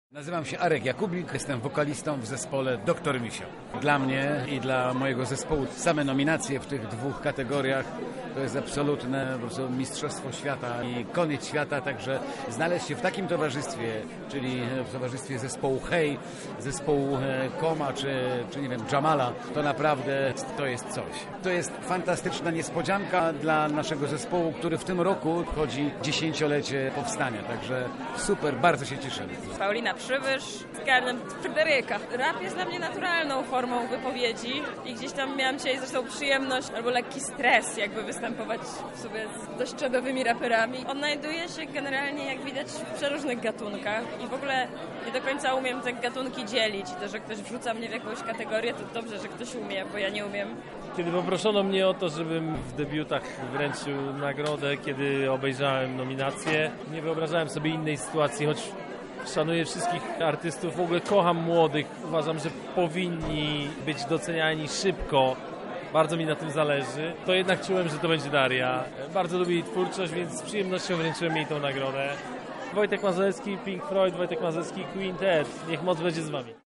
Rozdanie nagród miało miejsce w Teatrze Polskim.